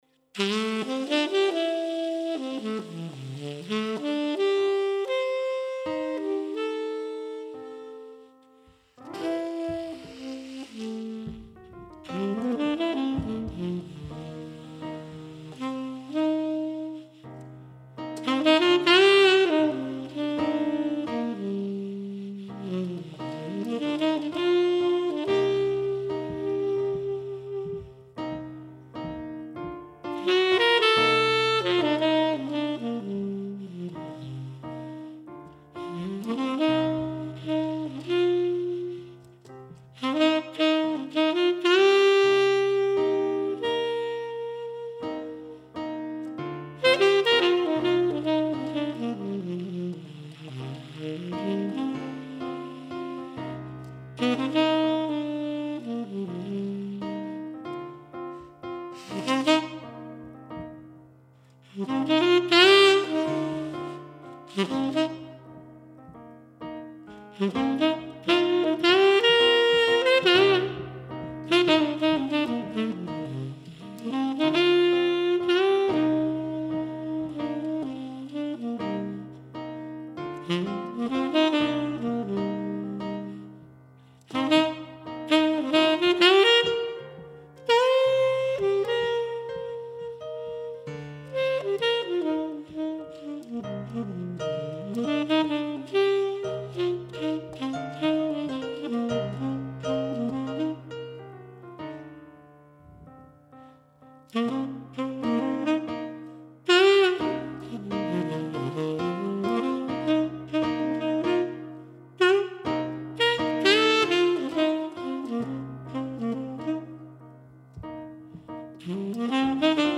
Piano mit Delay und einem melodischen Saxophon.